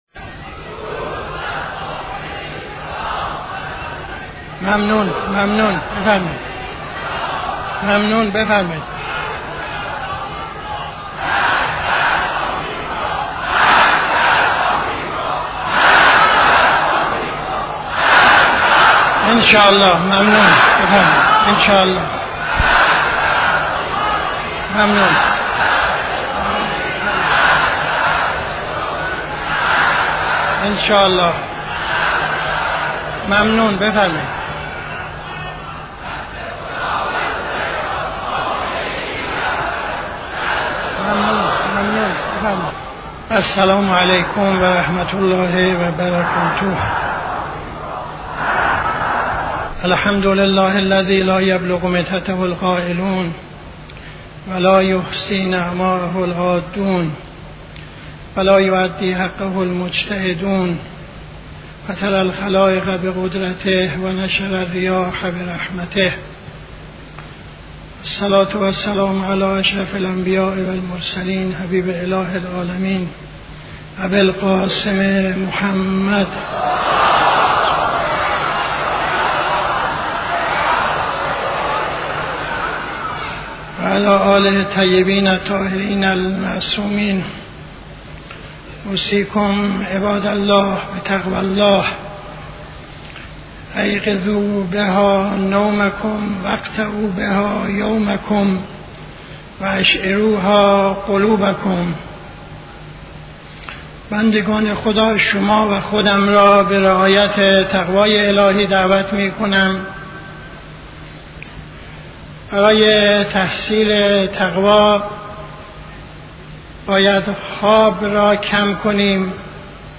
خطبه اول نماز جمعه 26-06-74